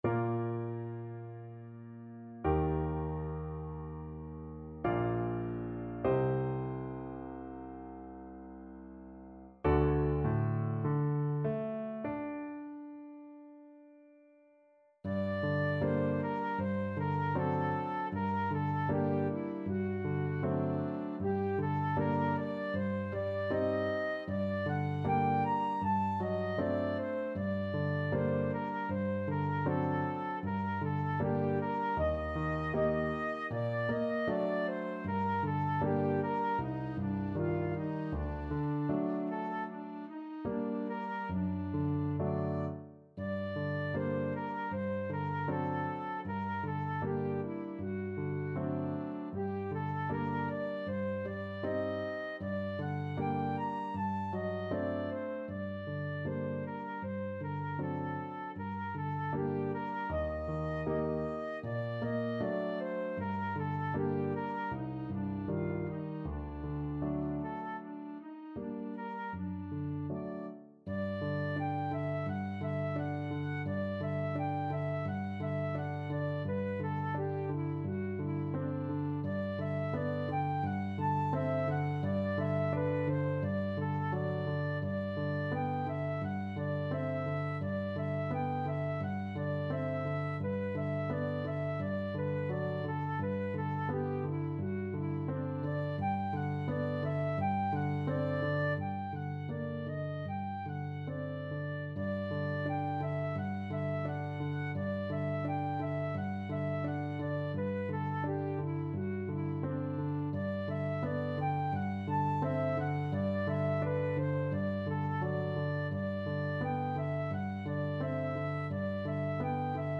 Flute
Bb major (Sounding Pitch) (View more Bb major Music for Flute )
4/4 (View more 4/4 Music)
Largo
Classical (View more Classical Flute Music)
mussorgsky_une_larme_FL.mp3